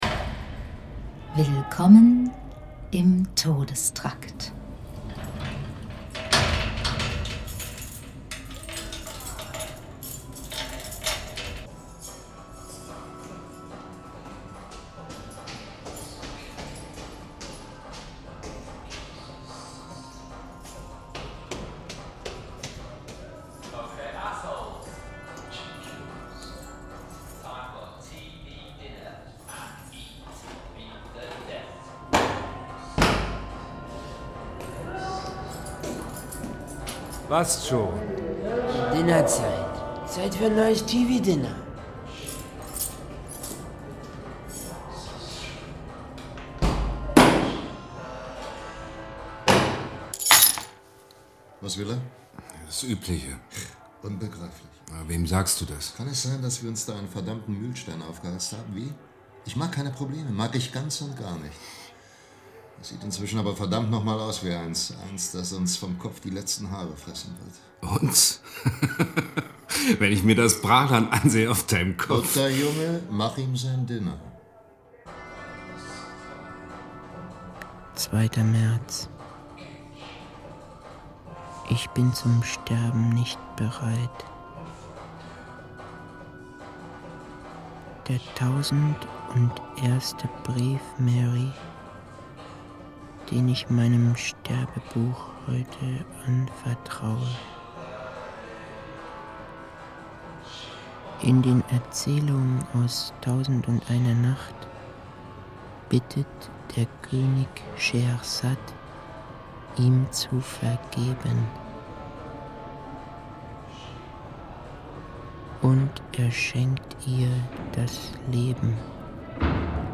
Radio | Hörspiel
Eine Produktion des WDR 1998